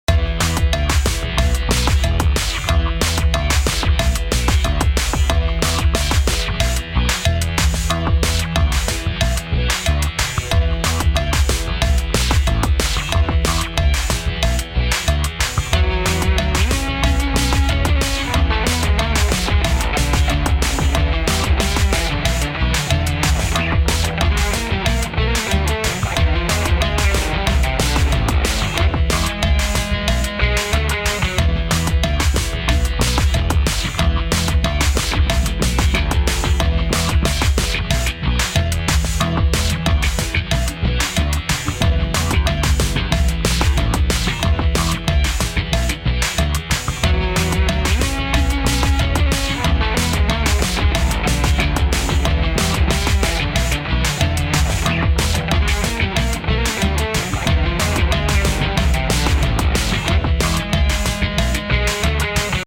home of the daily improvised booty and machines -
orginal 3 bass grooves